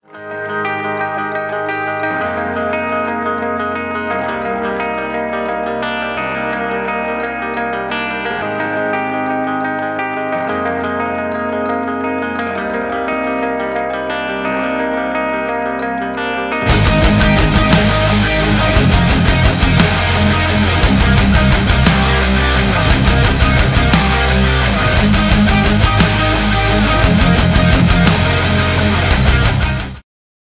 • Качество: 192, Stereo
гитара
без слов
соло на электрогитаре
Стиль: melodic metalcore